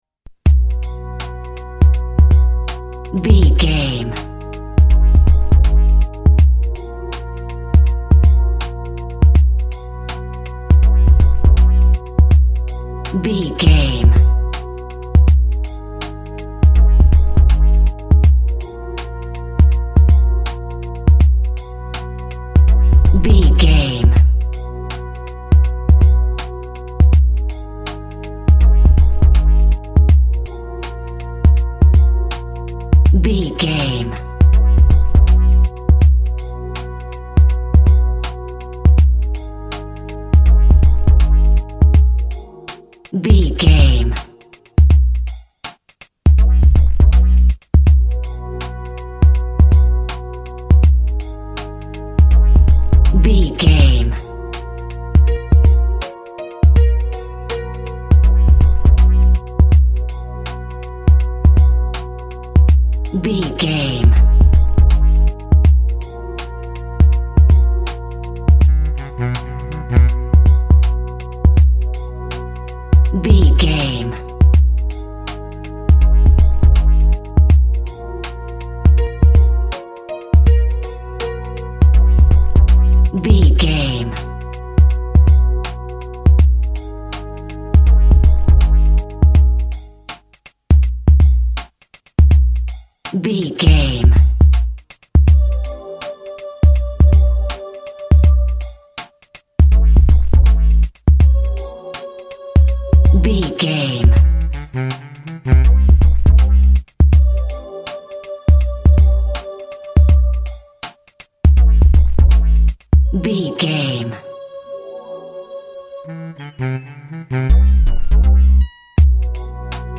Ionian/Major
synth lead
synth bass
hip hop synths
electronics